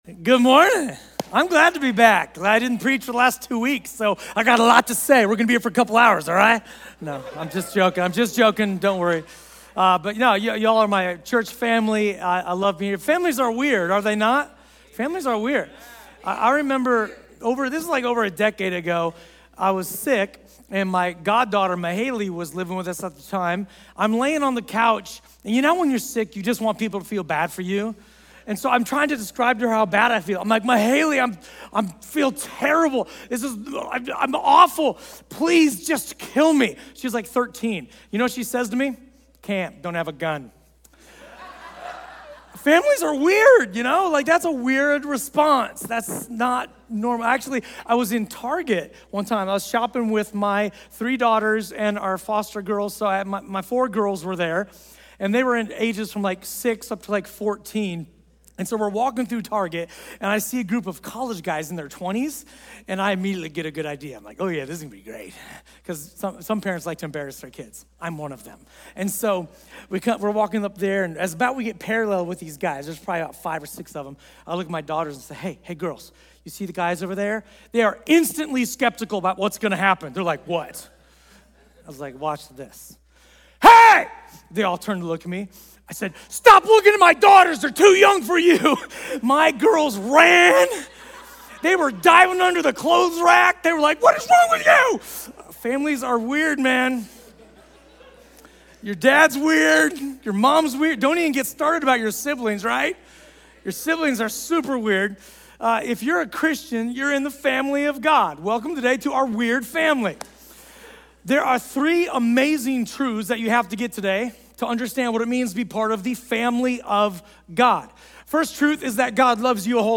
A sermon from the series "Revolution Sermon."